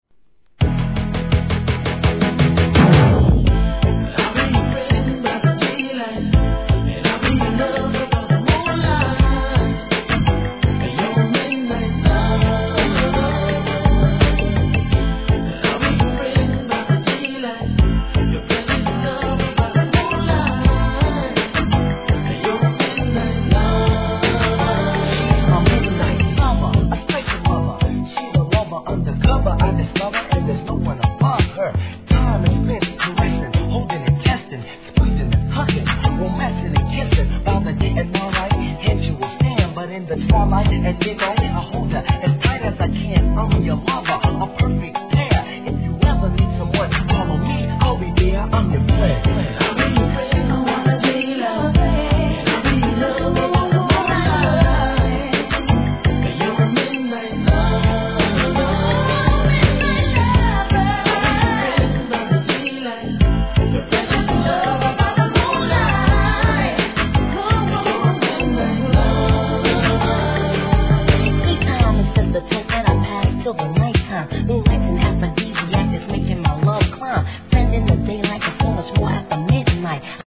HIP HOP/R&B
1990年、ソウル〜R&Bインディー物。デュエットでRAPを交えてのヴォーカルです。